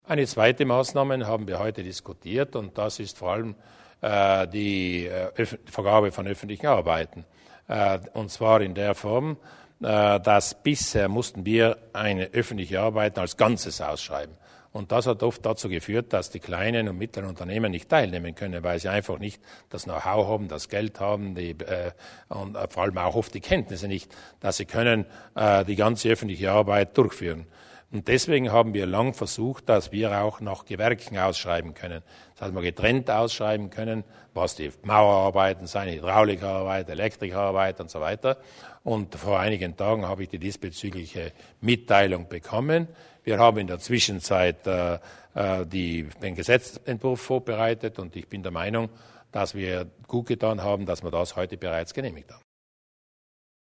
Landeshauptmann Durnwalder zur Aufteilung der Ausschreibung öffentlicher Bauaufträge